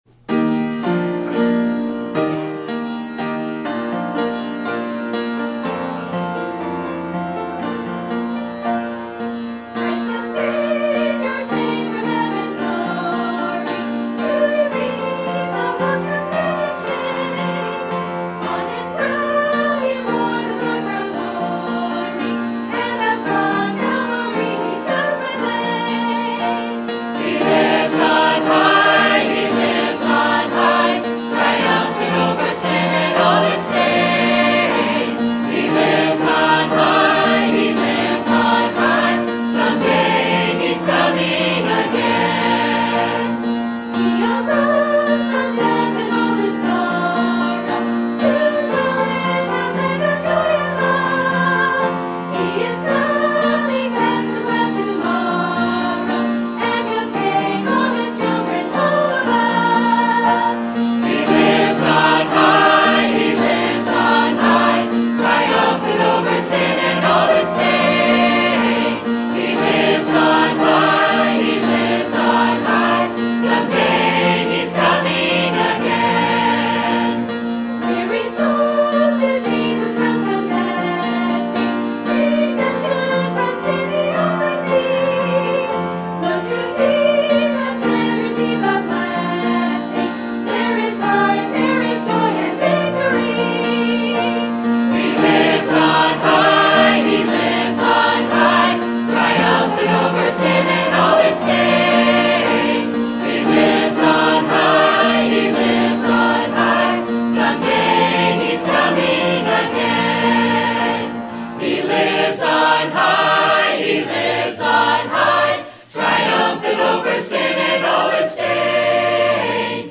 Sermon Topic: General Sermon Type: Service Sermon Audio: Sermon download: Download (28.03 MB) Sermon Tags: Job Repentance Trials Valley